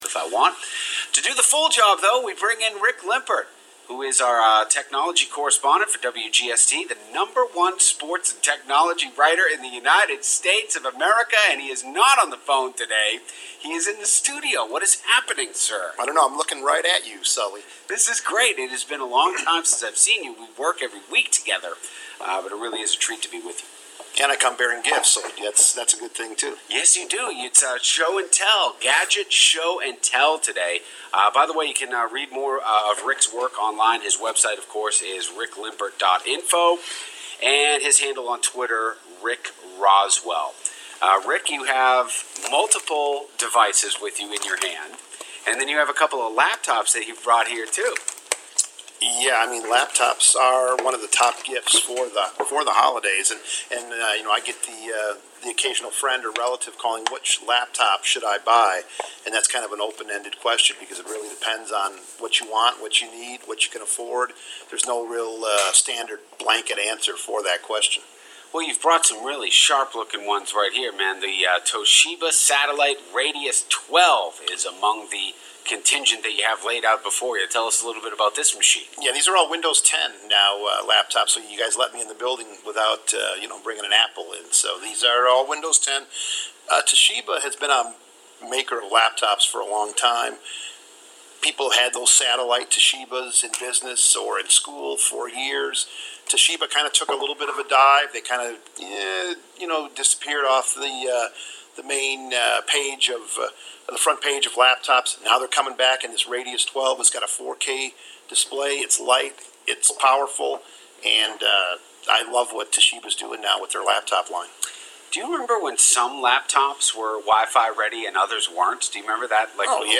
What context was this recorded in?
in studio this week